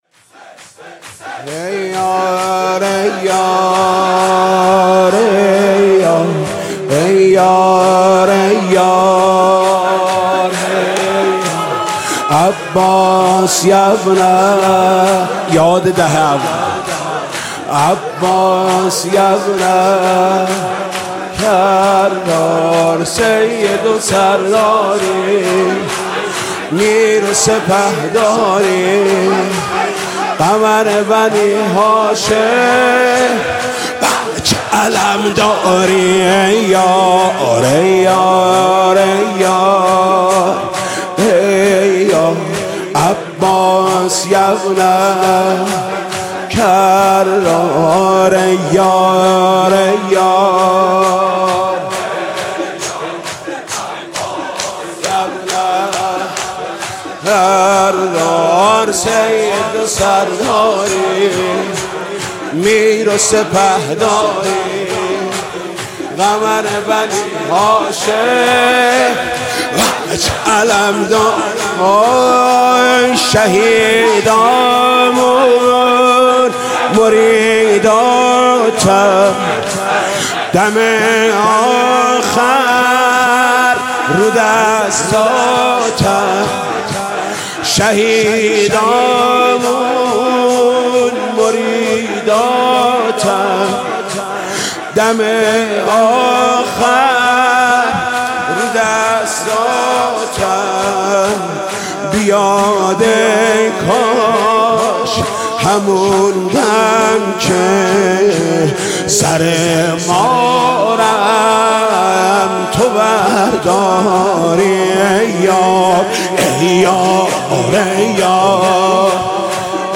ویژه شهادت امام حسن (شور)